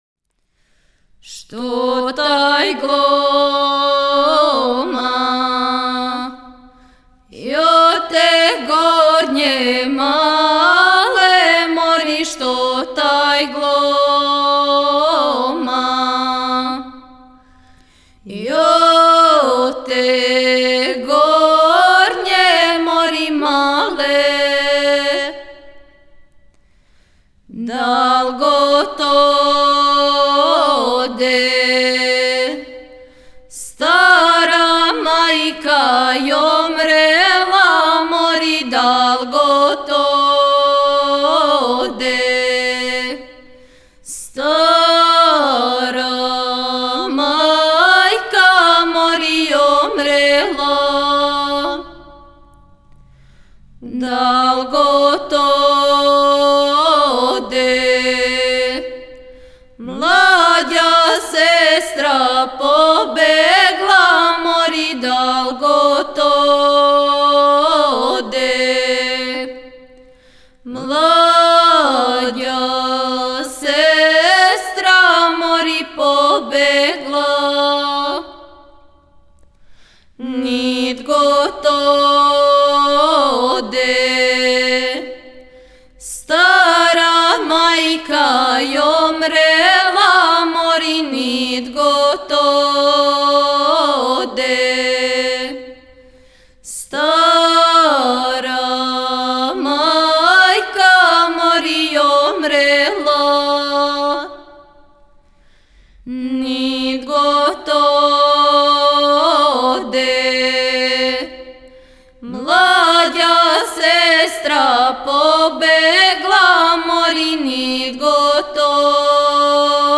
Порекло песме: Пасијане, Косово Начин певања: ? Напомена: Свадбена песма